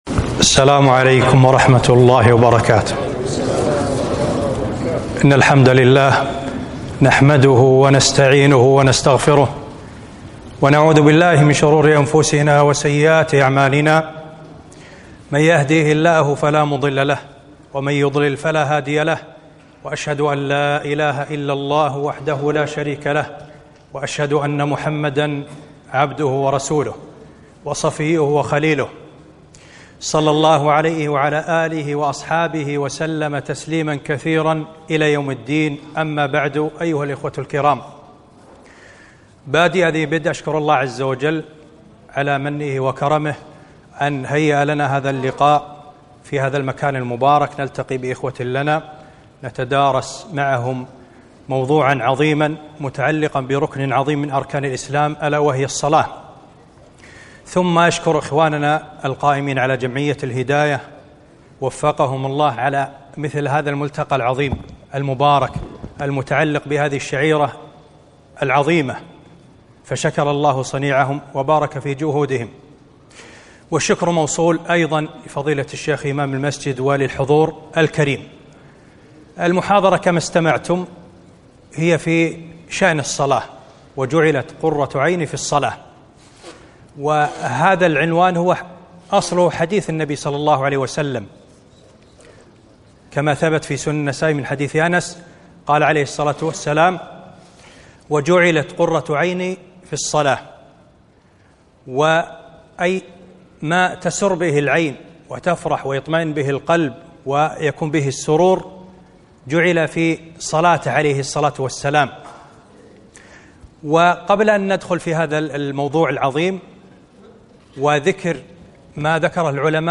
محاضرة - وجعلت قرة عيني في الصلاة